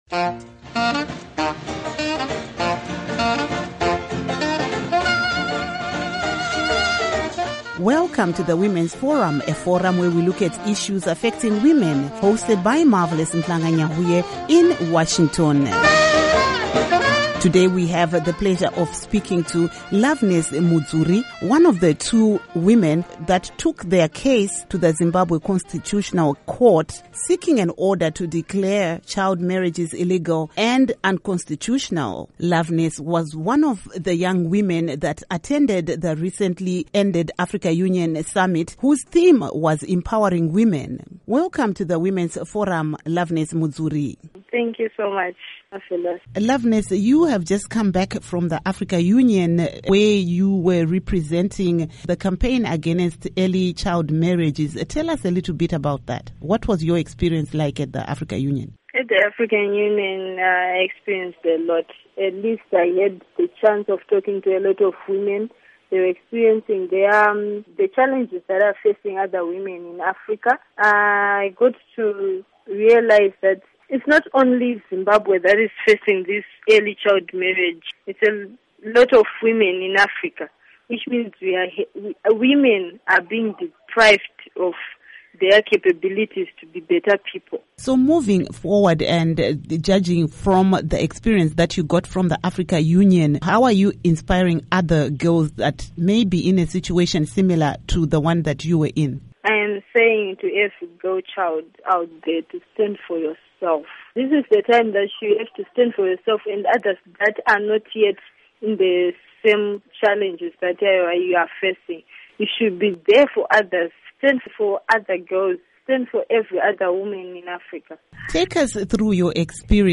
WOMEN'S FORUM: Interview